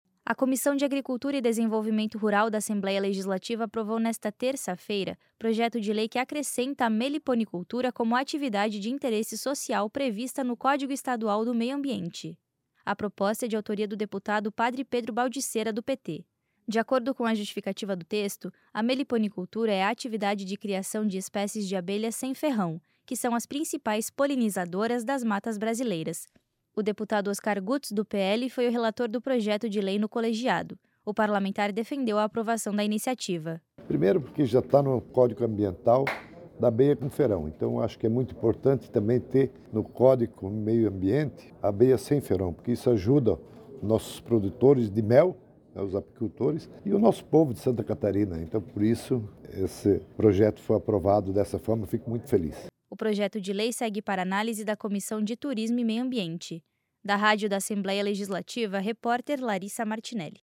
Entrevista com:
- deputado Oscar Gutz (PL), relator do projeto na Comissão de Agricultura.